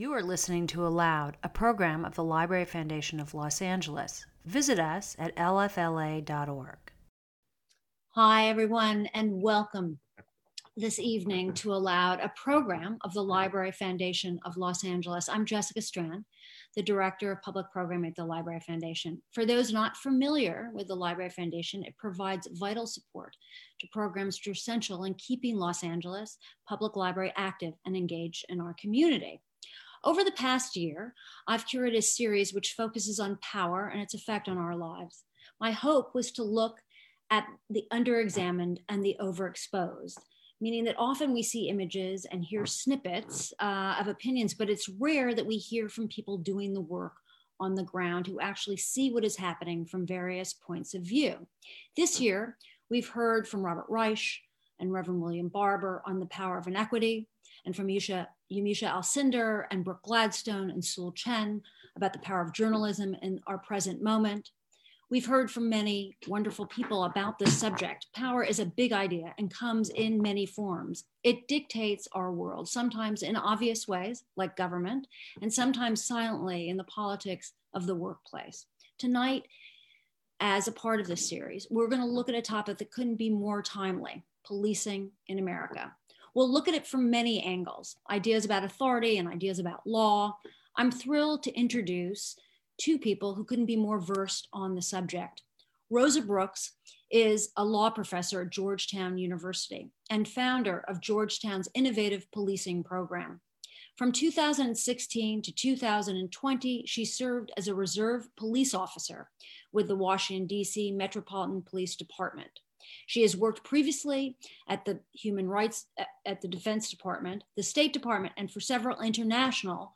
Join us for an immersive conversation as Brooks takes ALOUD audiences through a tour of duty to find a better way to protect our society.